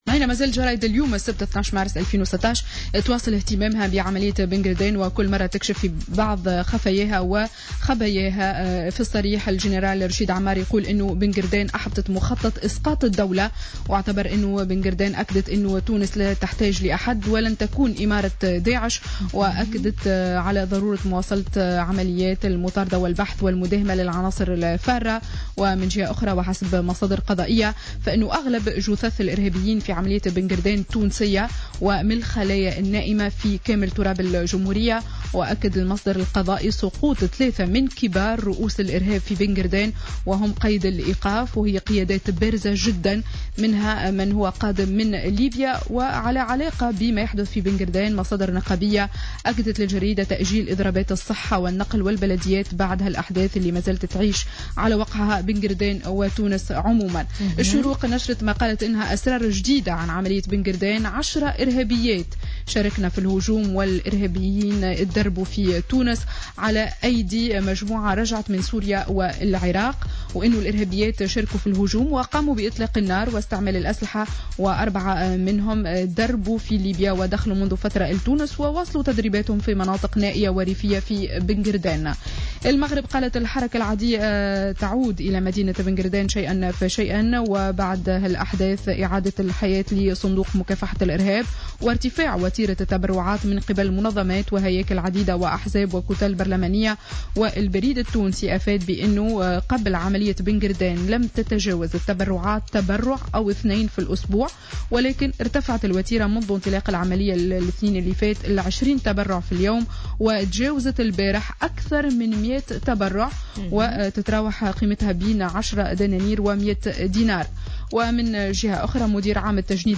Revue de presse du samedi 12 Mars 2016